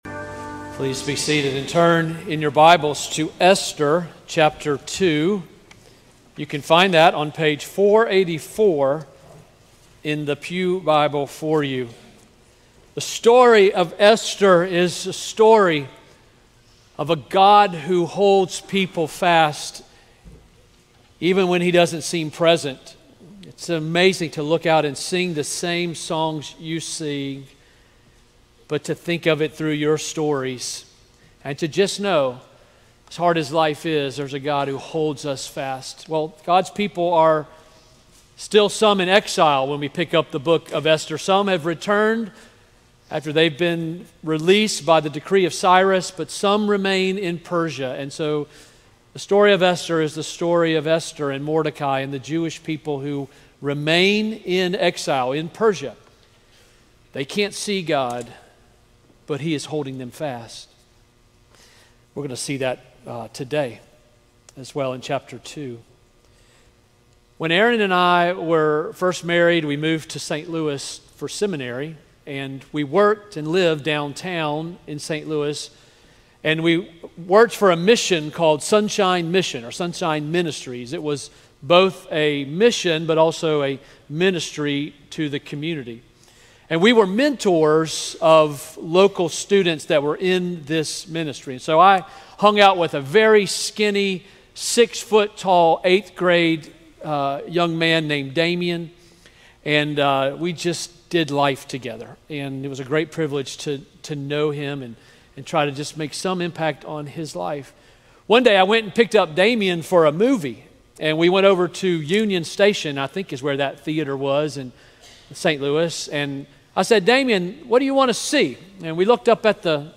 A sermon from the series "Esther." Esther 6:5-7:10 November 2, 2025 Morning